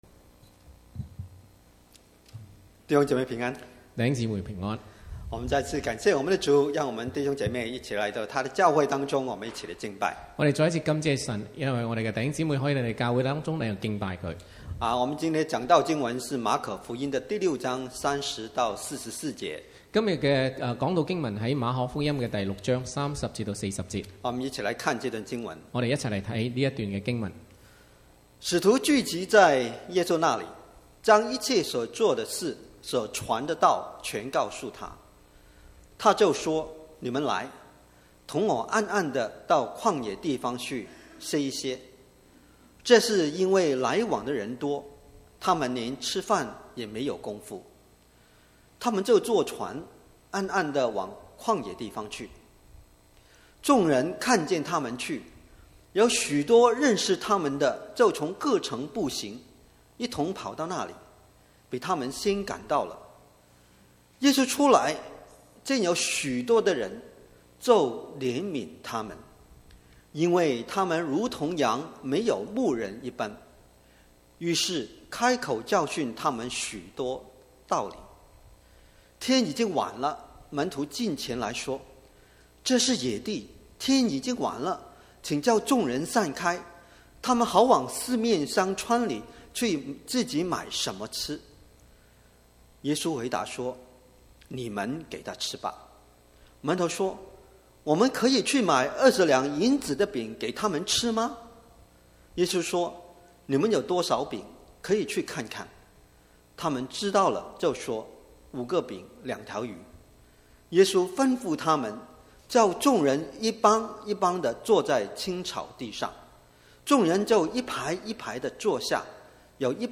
From Series: "Chinese Sermons"